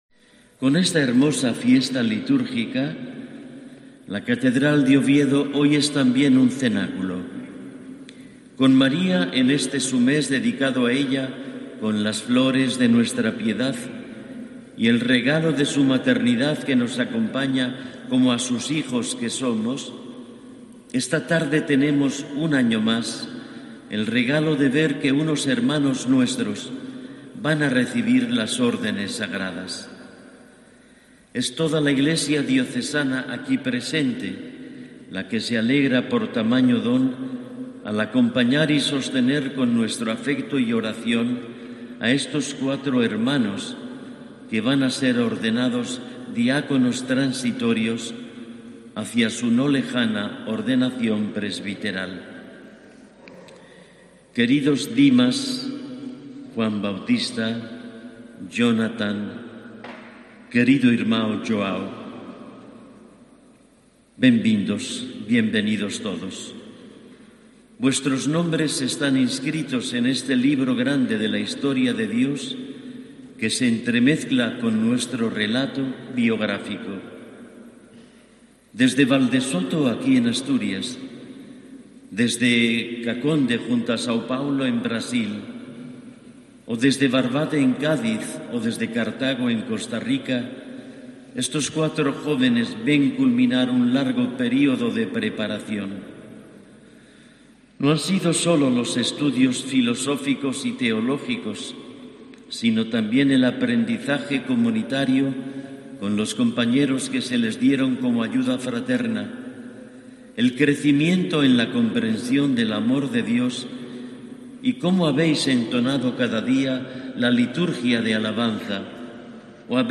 "Lo único que puedo hacer es entregarme a la misión que se me encomiende en la Diócesis", ha dicho, en COPE, uno de los nuevos diáconos